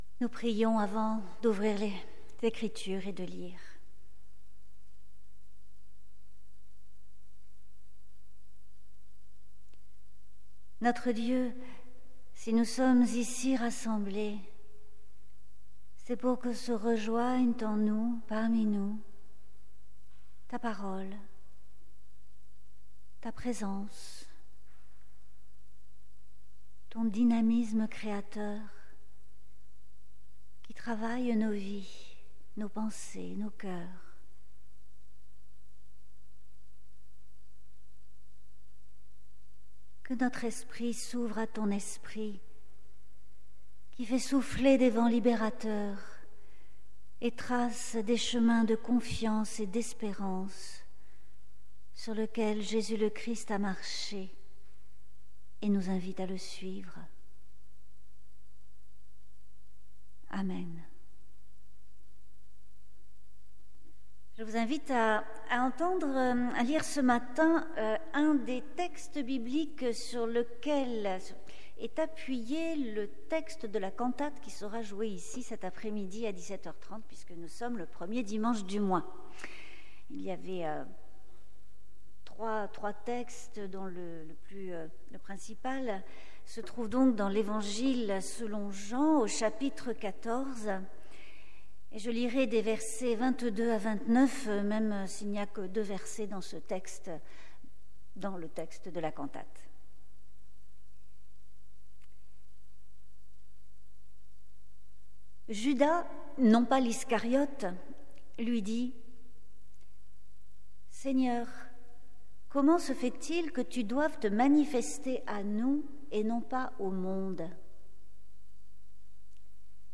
Prédication : Laver les pieds, élever les disciples